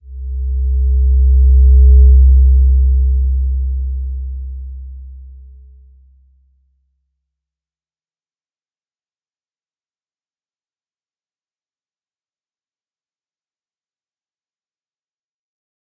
Slow-Distant-Chime-C2-p.wav